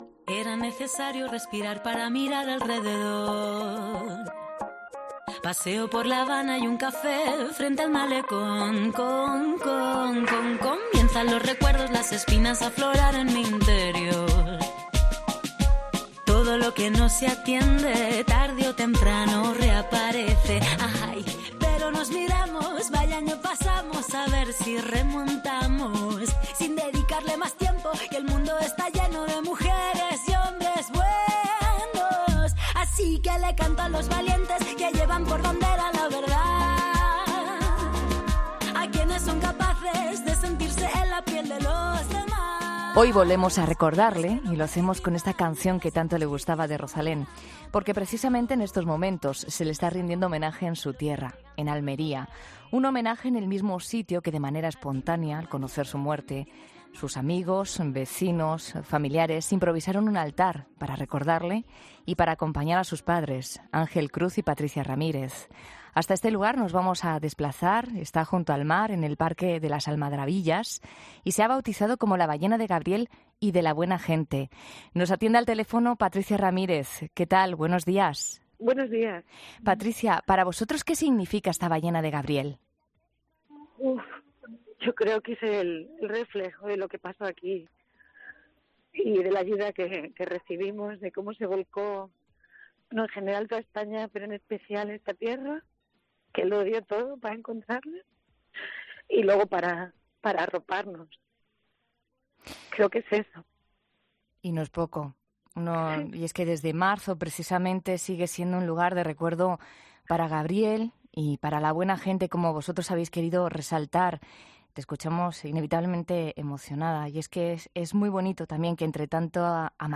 “Es un cuento con mucha alma", ha afirmado con la voz temblorosa.